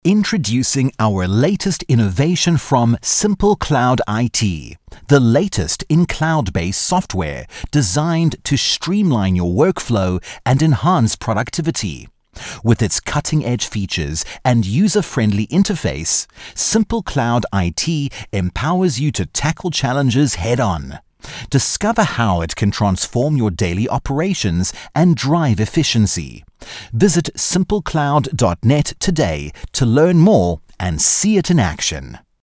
Unternehmensvideos
Herzlich, sanft und vielseitig.